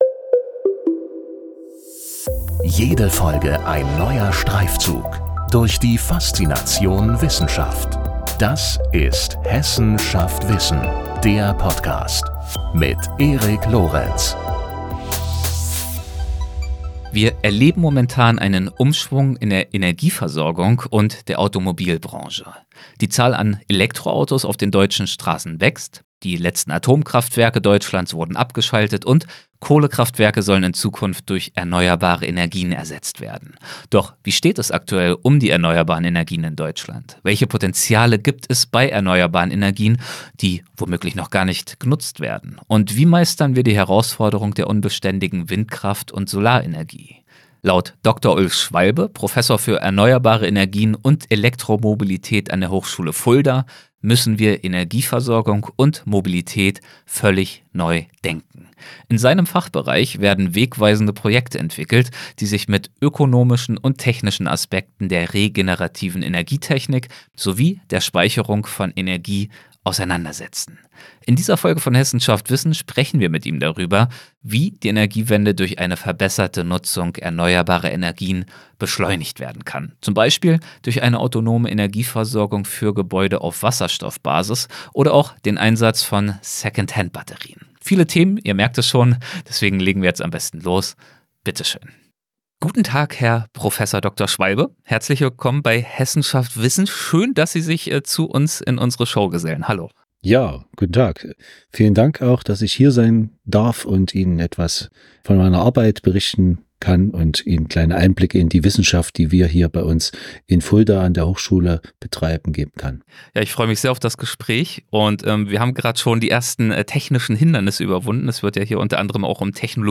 In dieser Folge von „Hessen schafft Wissen“ sprechen wir mit ihm darüber, wie die Energiewende durch eine verbesserte Nutzung erneuerbarer Energien beschleunigt werden kann, z.B. durch eine autonome Energieversorgung für Gebäude auf Wasserstoffbasis oder den Einsatz von Second-Hand-Batterien.